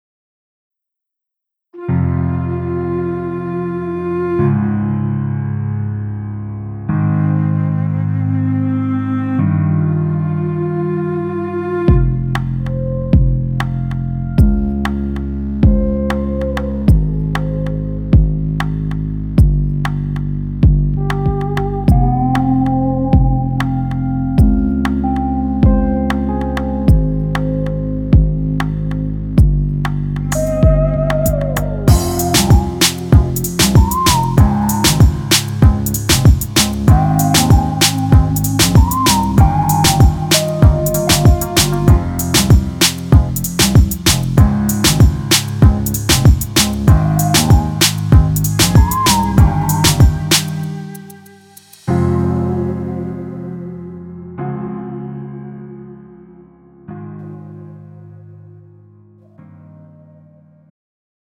음정 -1키 장르 가요 구분 Pro MR